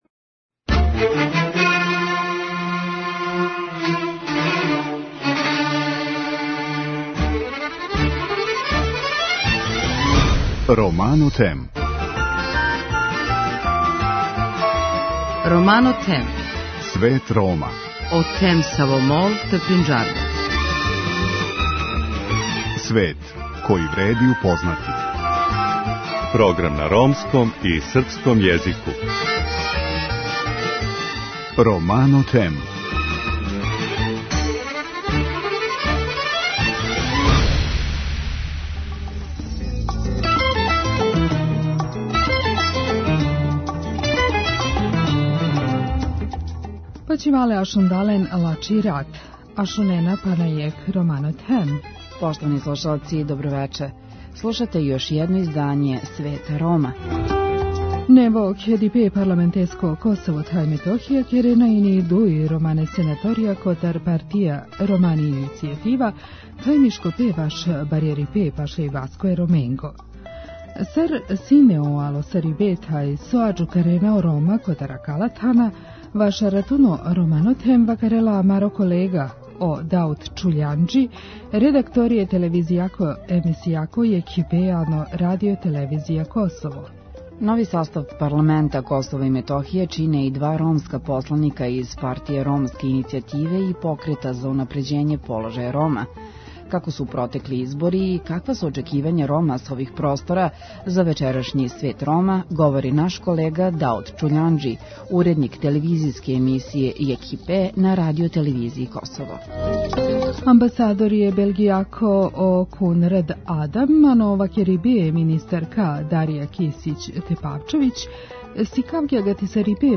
Вести на ромском језику